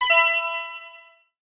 06698 gem collect ding
bonus collect computer game gem girl jewel sfx sound effect free sound royalty free Sound Effects